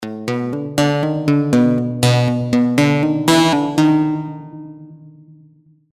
flip with sine shape